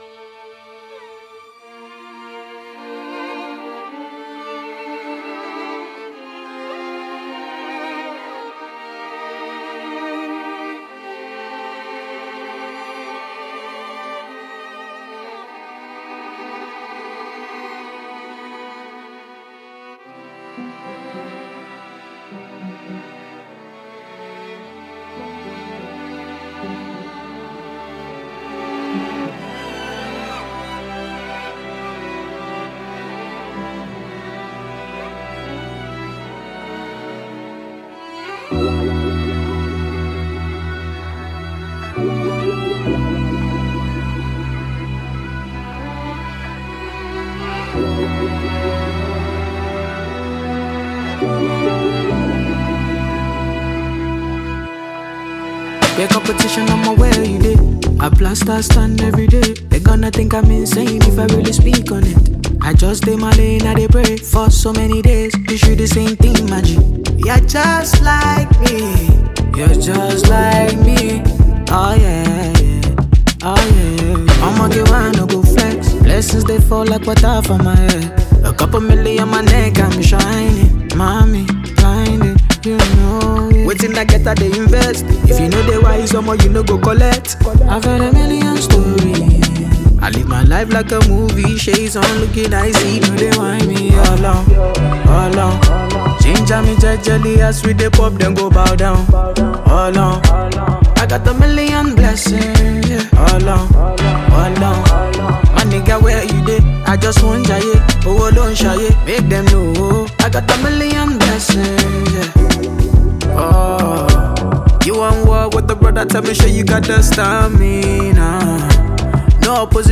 Grammy Award winning Nigerian heavyweight Afrobeat Singer